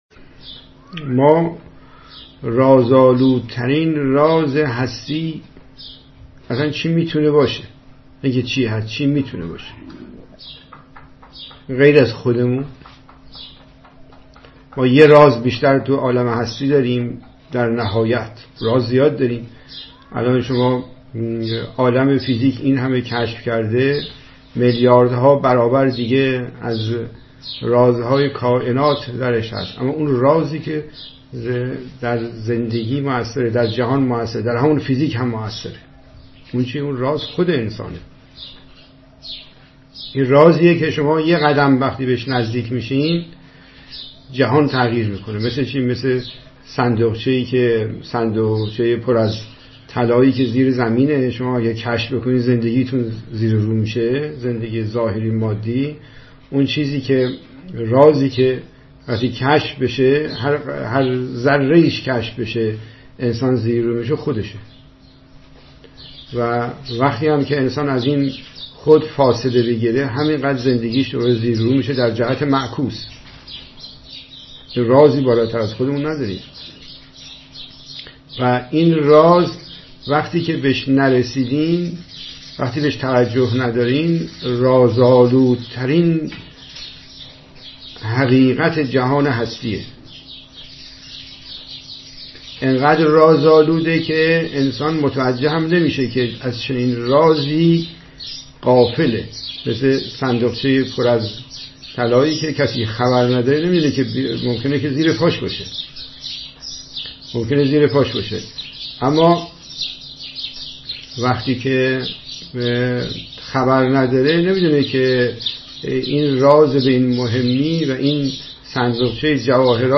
گفت‌وگو دربارۀ رمضان (انجام تمرین روزه‌داران کوهستان یمن) (۲)